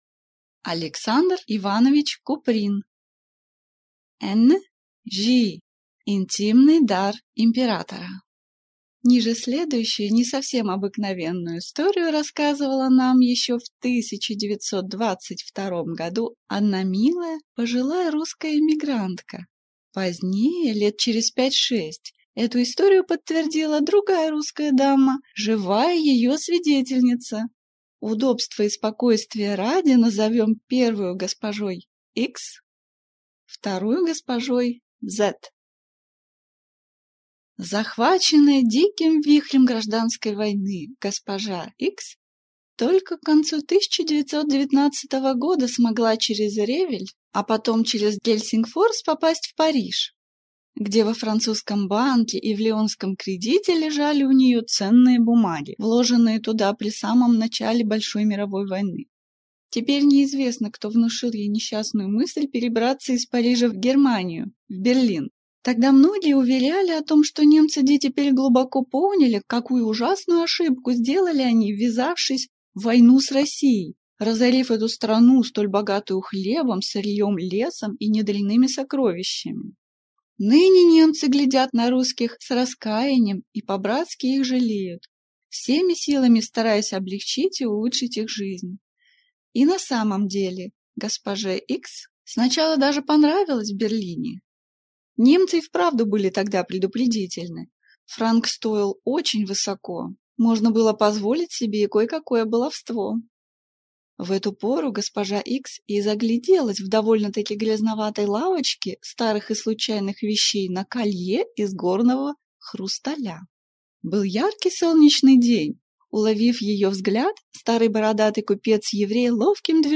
Аудиокнига «N.-J.» Интимный дар императора | Библиотека аудиокниг